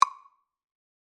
Metronome - JG Music Lessons
tick-for-metronome-1.mp3